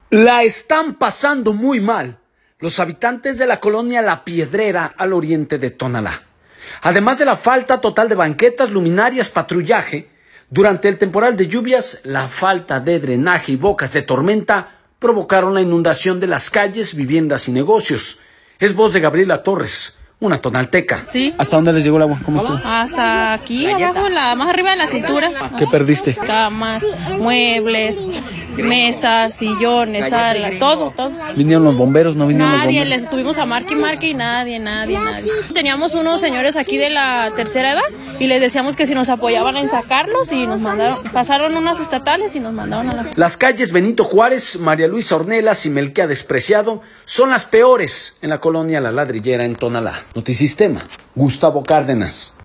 una tonalteca.